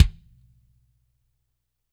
-DRY NS 12-R.wav